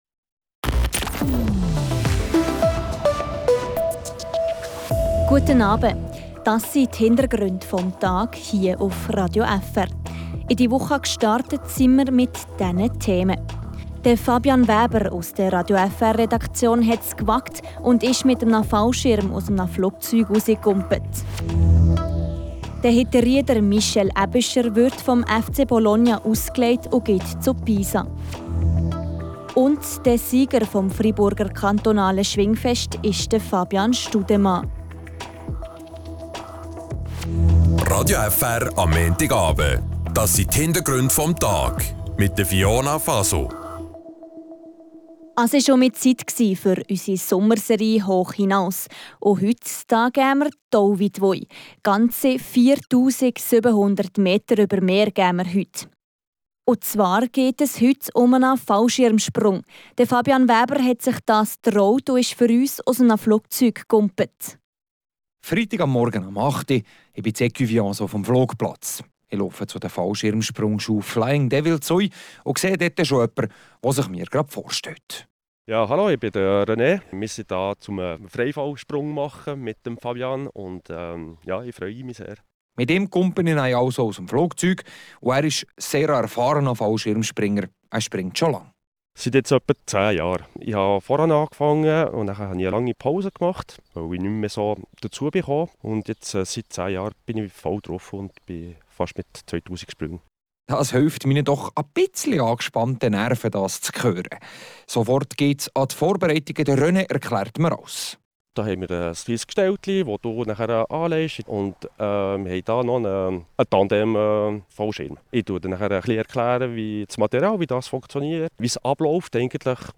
Beschreibung vor 8 Monaten Ein Traum, den ich mir erfüllt habe: ein Fallschirmsprung. Eine Reportage über den Sprung, aber auch ein Selbstexperiment. Denn was passiert in unserem Kopf bei so einem Sprung?